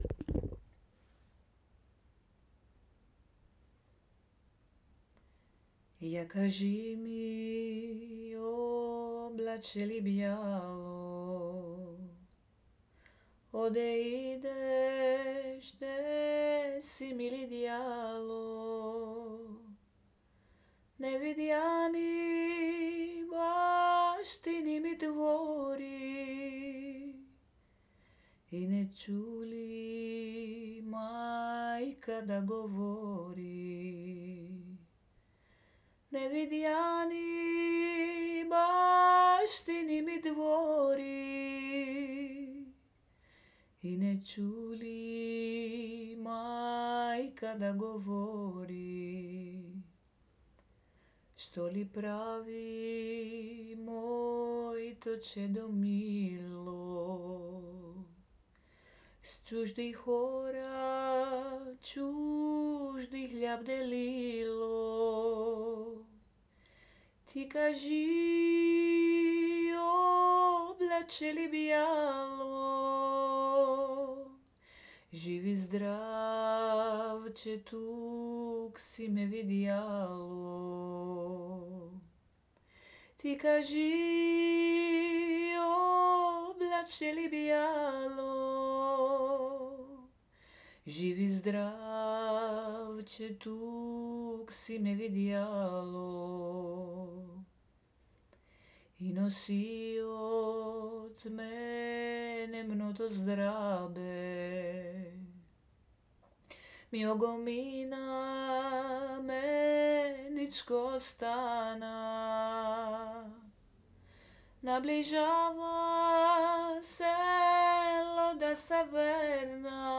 Popular Bulgarian song written in 1916 by Gencho Negentsov
(voice)